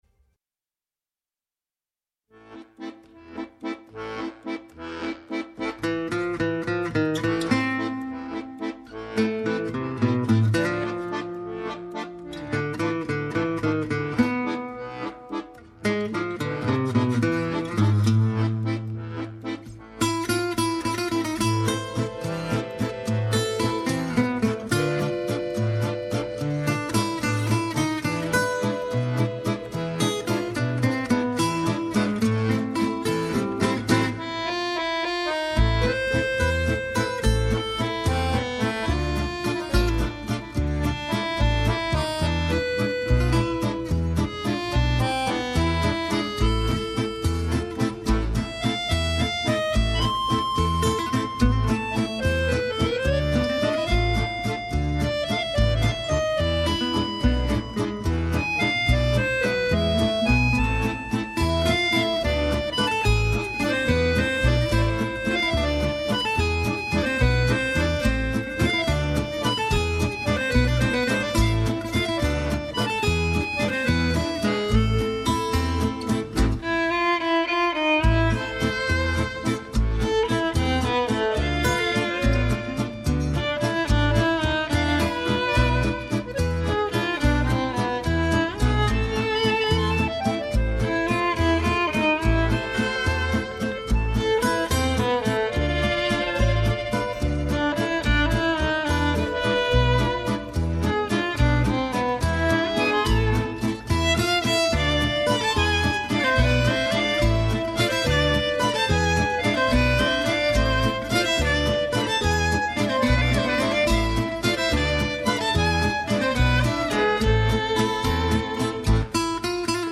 ΣΥΝΕΝΤΕΥΞΕΙΣ